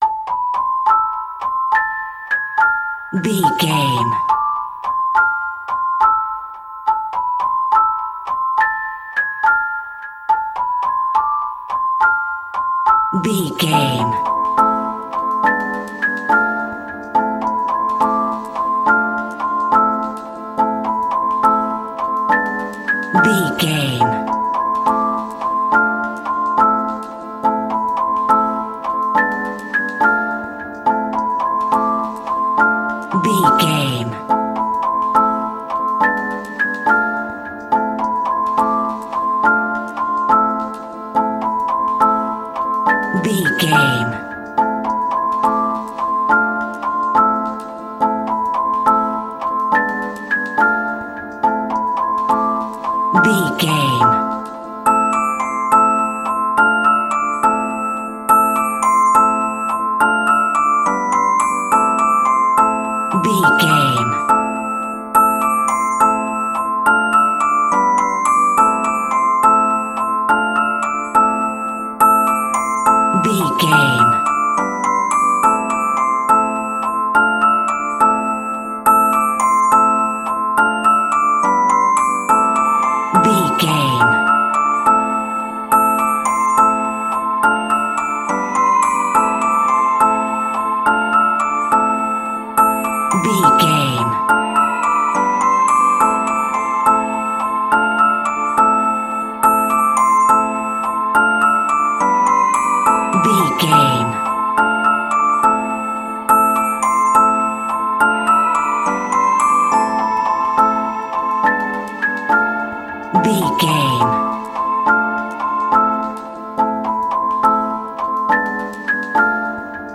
Aeolian/Minor
scary
ominous
haunting
eerie
piano
percussion
horror music
Horror Pads
Horror Synths